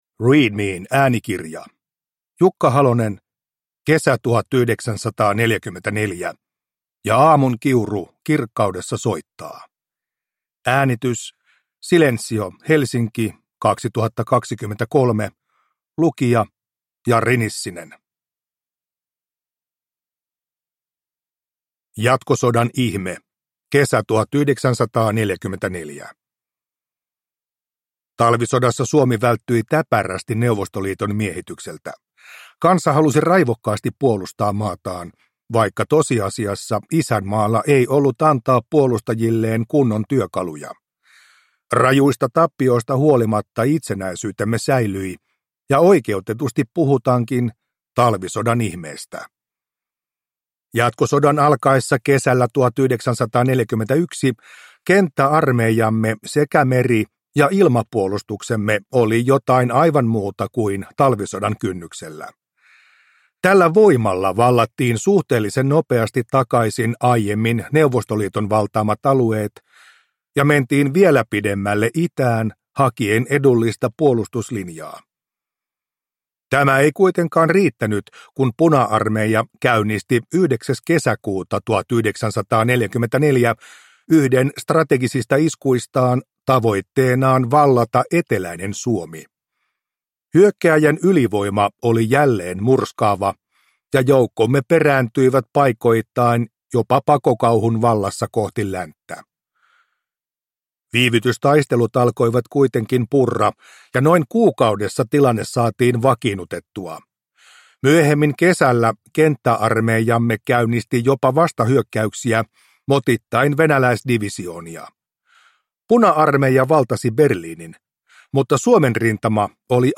Kesä 1944 – Ljudbok